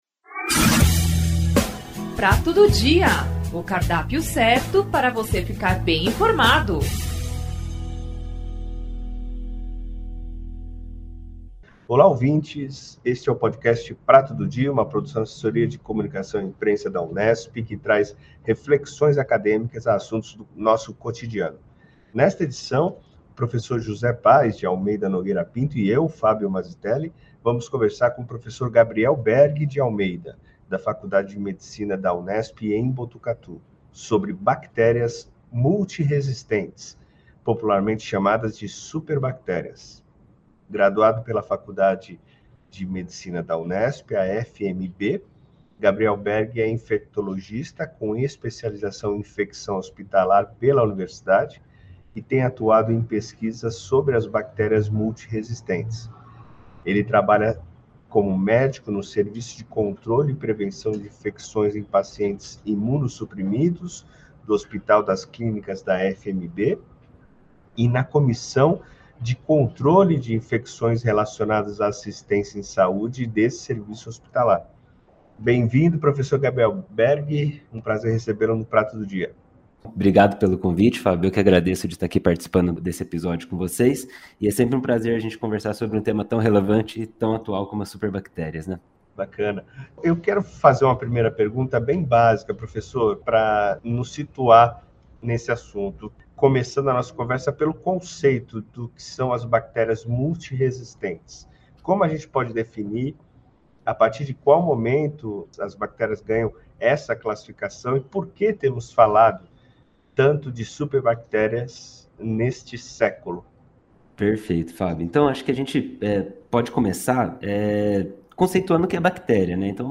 O “Prato do Dia”, Podcast da Assessoria de Comunicação e Imprensa da Reitoria da Unesp é um bate-papo e uma troca de ideias sobre temas de interesse da sociedade. De maneira informal debateremos tópicos atuais, sempre na perspectiva de termos o contra-ponto, o diferencial.